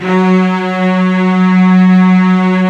55u-va03-F#2.wav